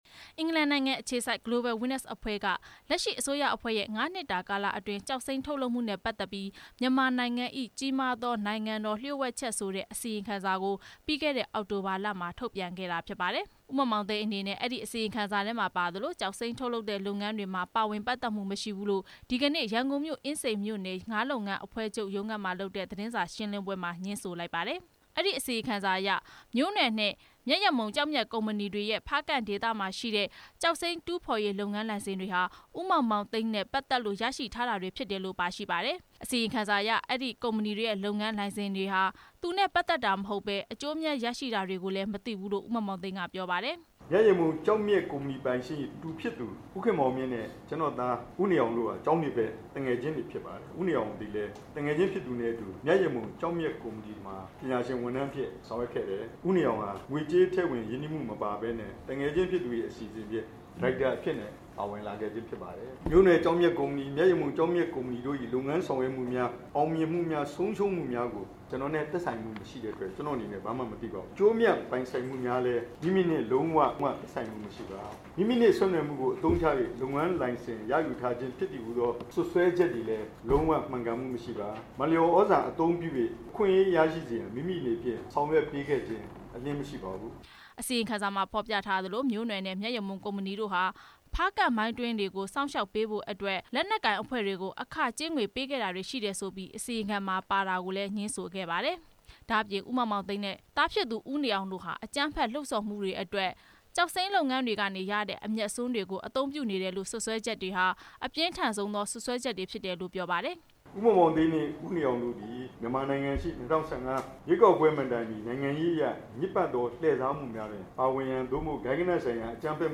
ရန်ကုန်တိုင်းဒေသကြီး အင်းစိန်မြို့နယ်မှာရှိတဲ့ မြန်မာနိုင်ငံငါးလုပ်ငန်းအဖွဲ့ချုပ်ရုံးမှာ မနေ့က ကျင်းပတဲ့ သတင်းစာရှင်းလင်းပွဲမှာ ဦးမောင်မောင်သိမ်းက ပြောသွားတာဖြစ်ပါတယ်။